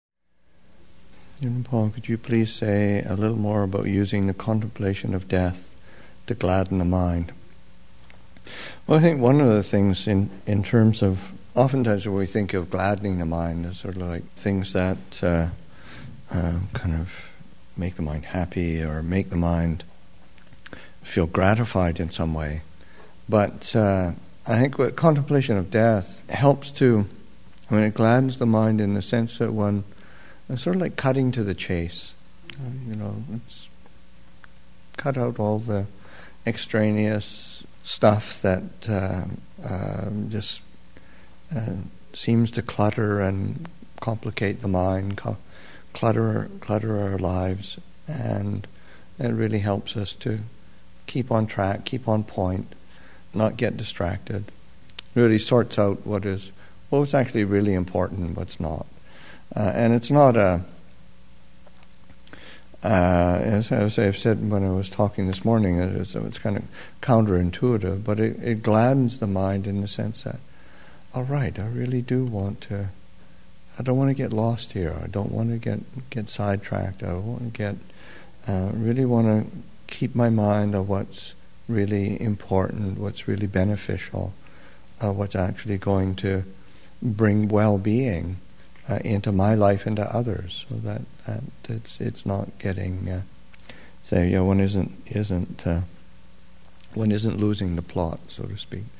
Thanksgiving Retreat 2016, Session 7 – Nov. 25, 2016